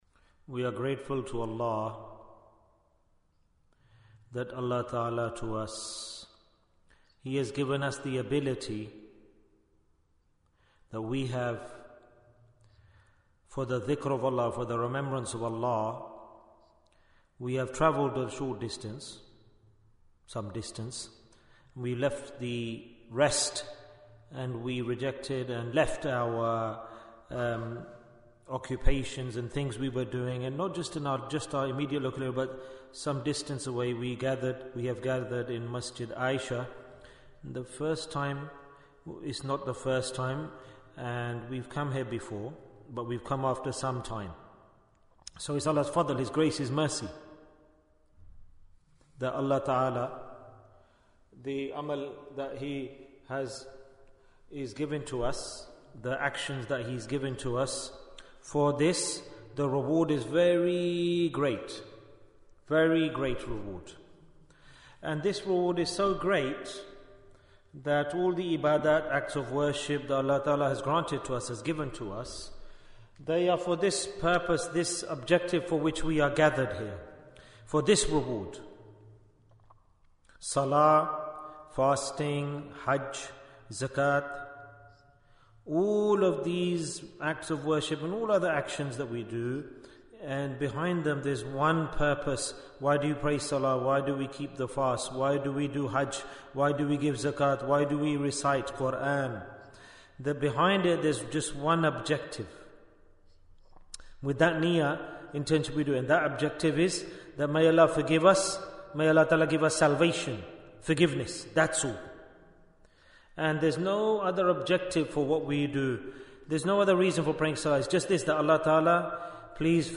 Door to Forgiveness Bayan, 24 minutes23rd November, 2024